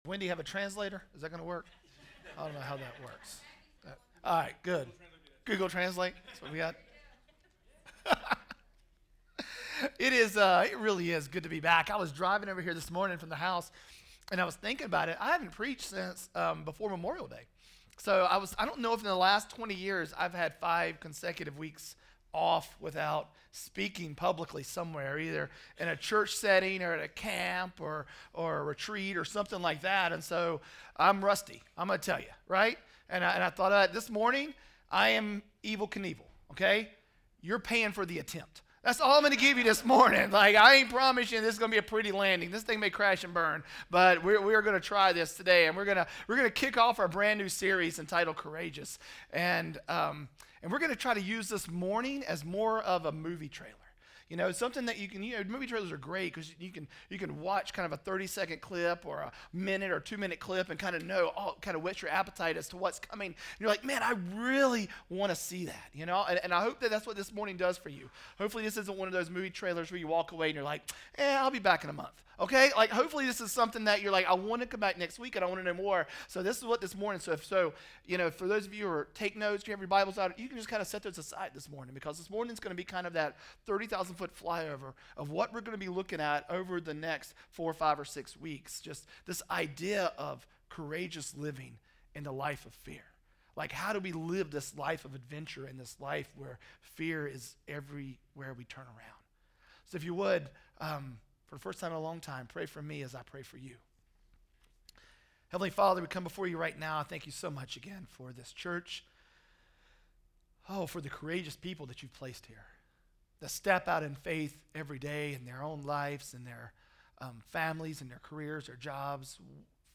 Project:Re3 RE3 Sermon Audio